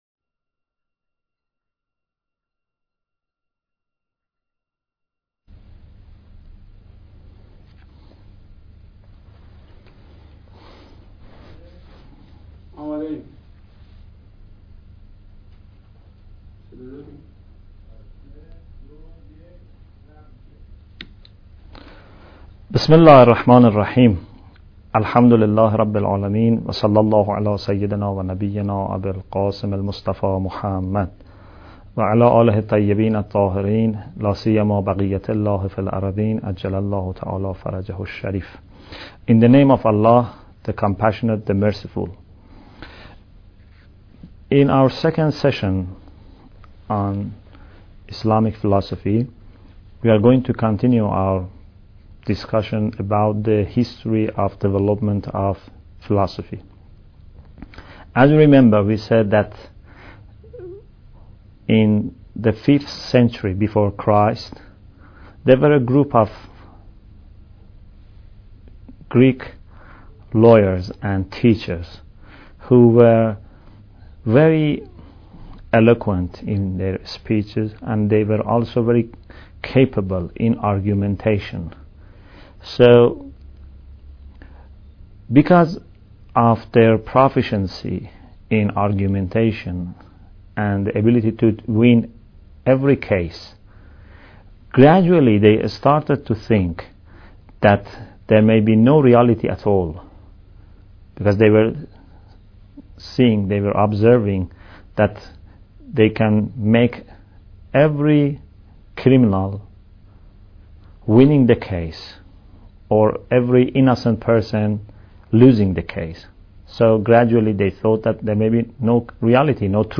Bidayat Al Hikmah Lecture 2